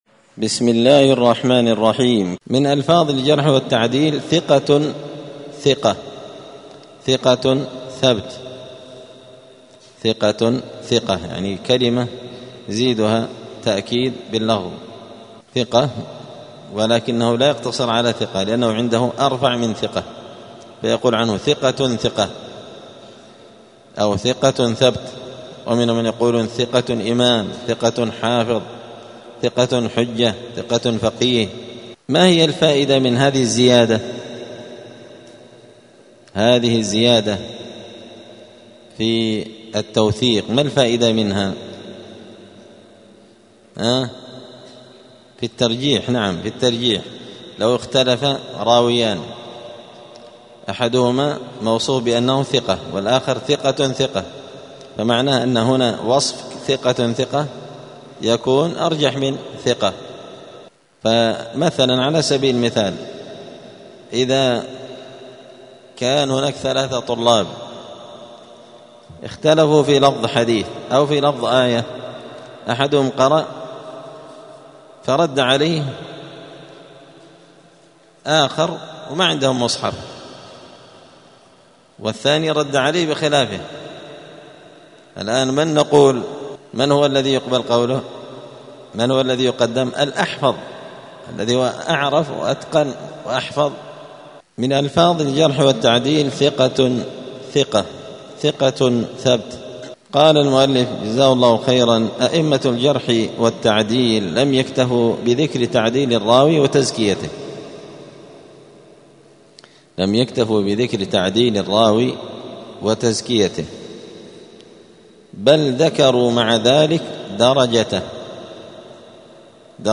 *الدرس الرابع بعد المائة (104) باب من ألفاظ الجرح والتعديل {ثقة ثقة، ثقة ثبت}*